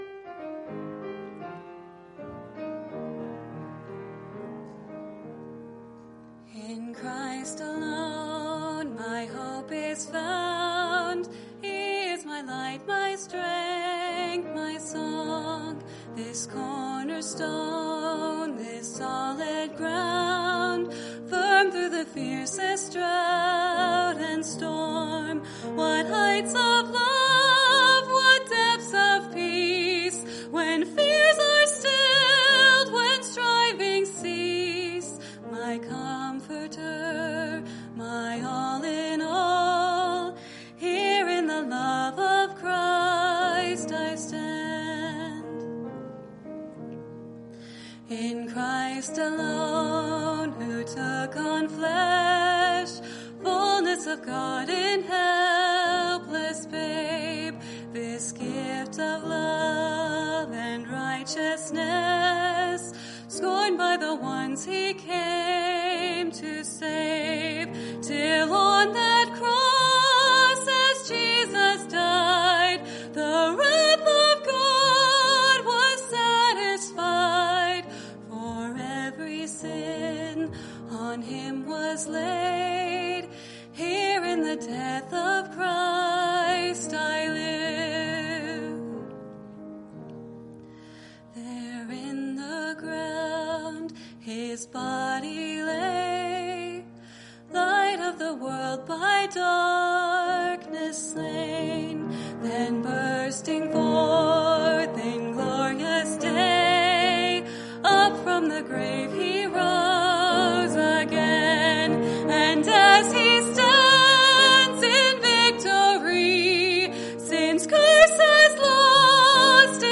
Special Music